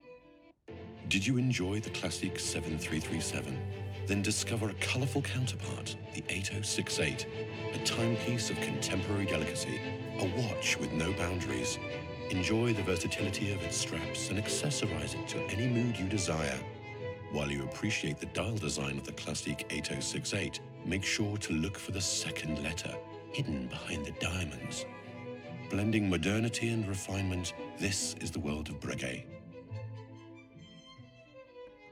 Profonde, Distinctive, Mature, Chaude, Corporative
Téléphonie